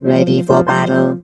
rick_start_vo_02.wav